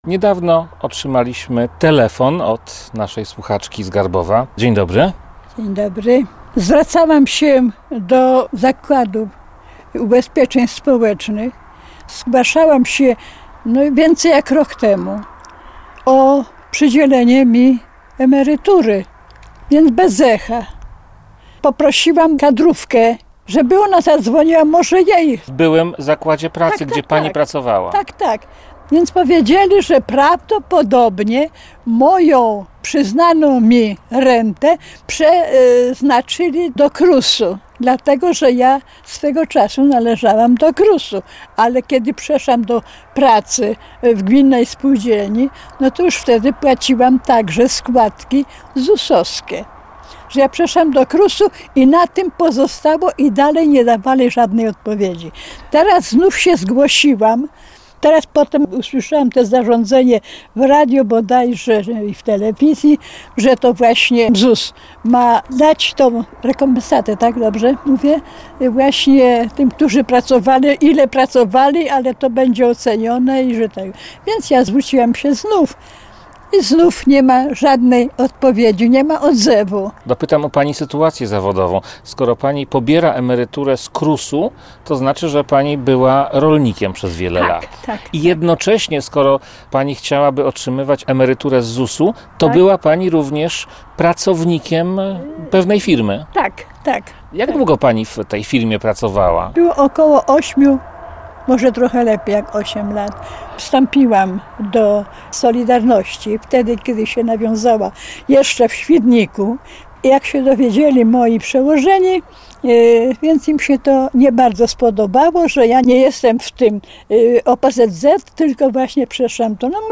Słuchaczka z Garbowa 2.